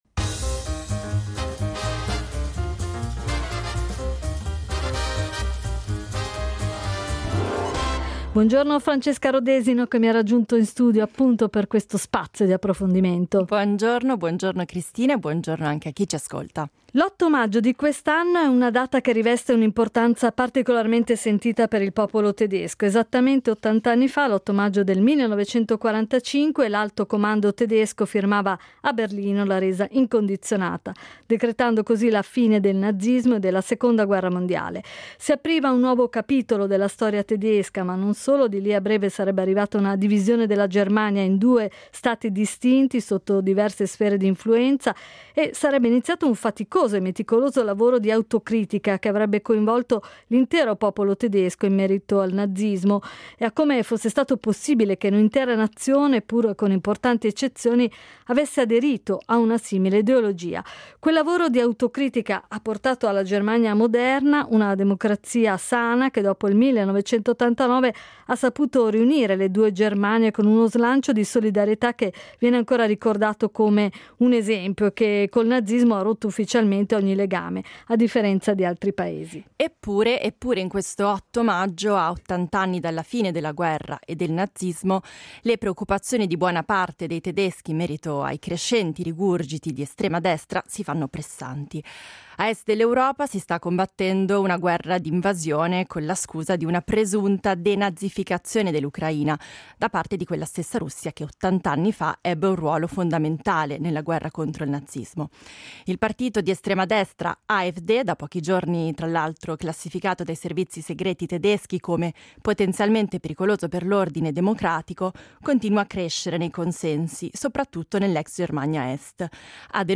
Ad Alphaville ne abbiamo parlato con due ospiti: